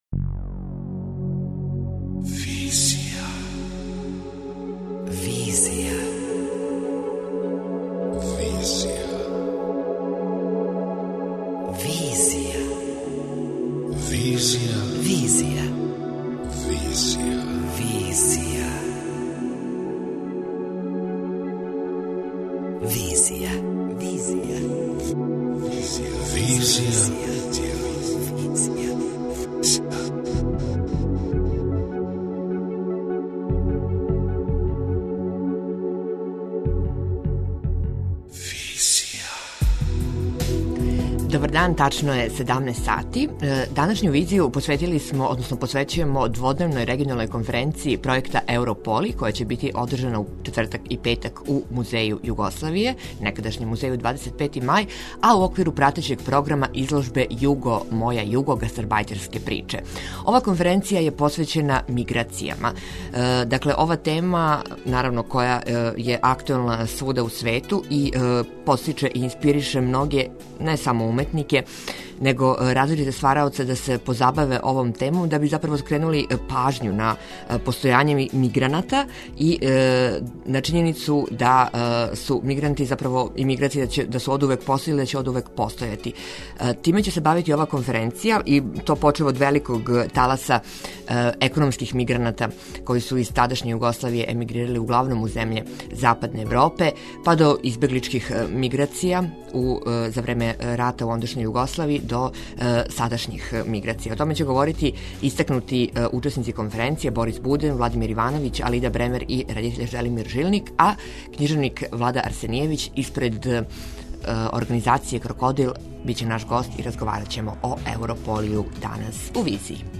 преузми : 27.29 MB Визија Autor: Београд 202 Социо-културолошки магазин, који прати савремене друштвене феномене.